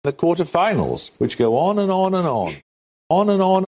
A good way to illustrate this distinction is with native speaker recordings of the common phrase on and on, /ɒn ən ɒn/: